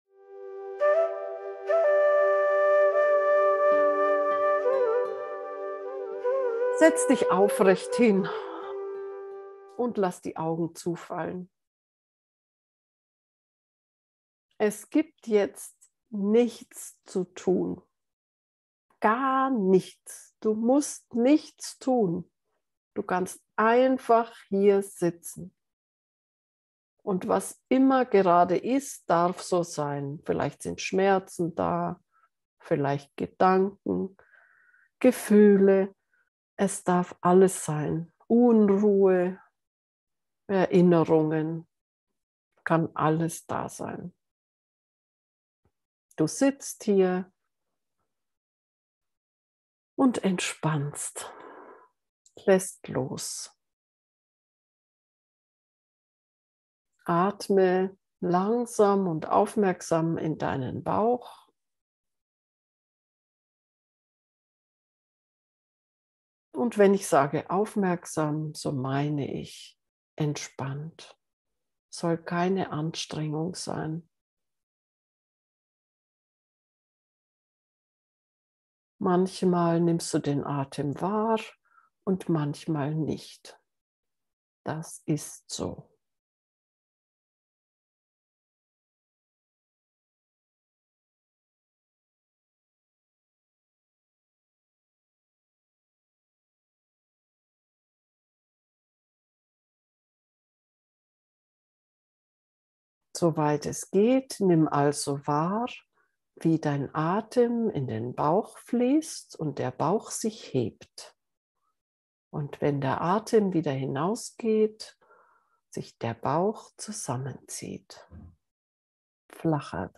Geführte Meditationen